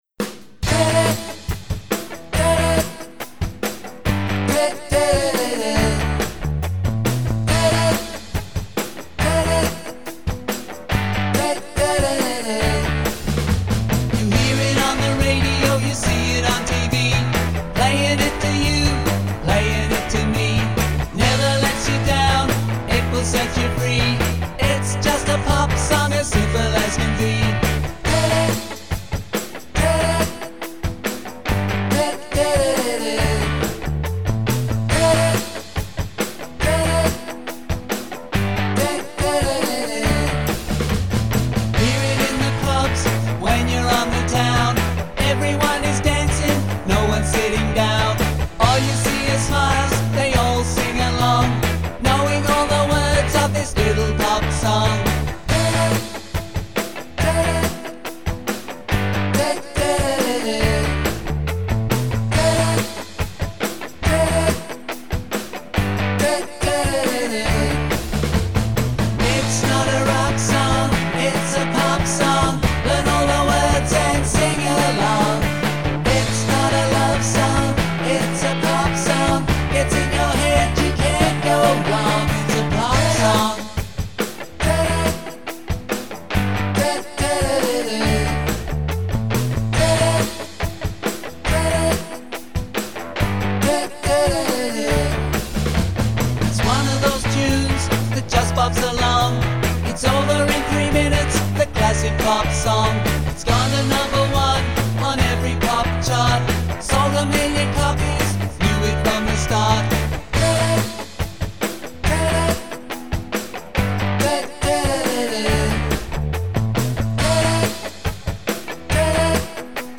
retro 60s garage pop
containing 14 original handcrafted catchy retro pop tunes .